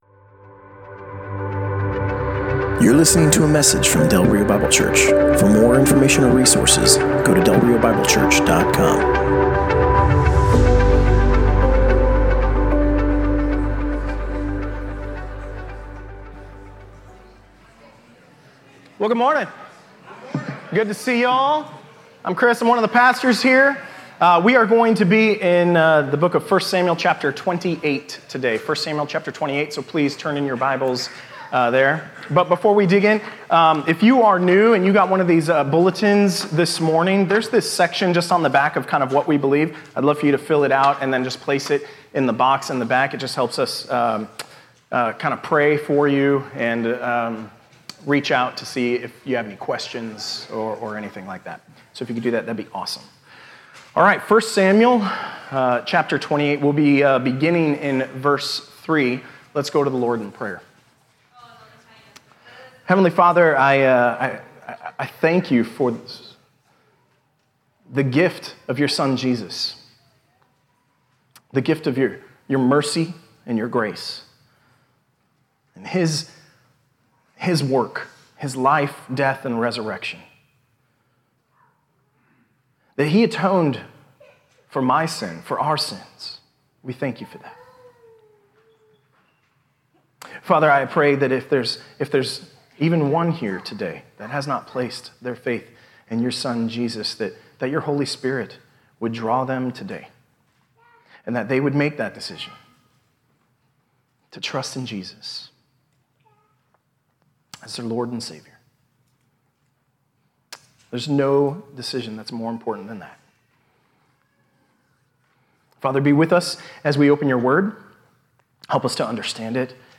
Passage: 1 Samuel 28:3-25 Service Type: Sunday Morning